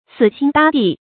死心搭地 注音： ㄙㄧˇ ㄒㄧㄣ ㄉㄚ ㄉㄧˋ 讀音讀法： 意思解釋： 見「死心塌地」。